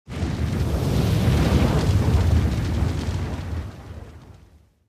soceress_skill_inferno_02_fire.mp3